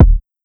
Feeling Kick.wav